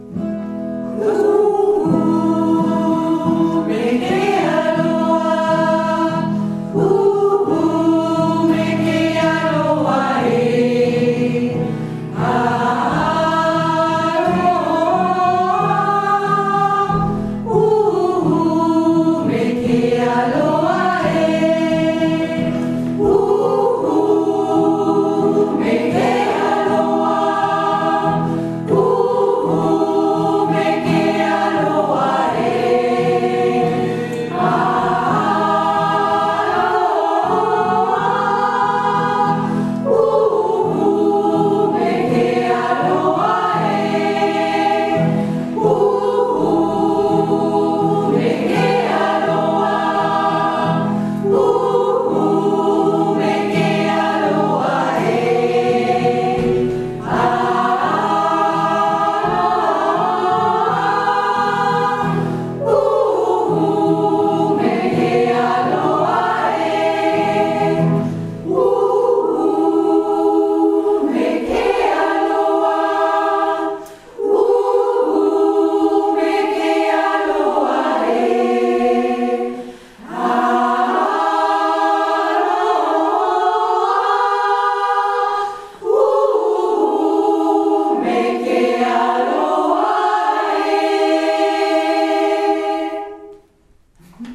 Unser Maori Segnungslied (1.4 MB)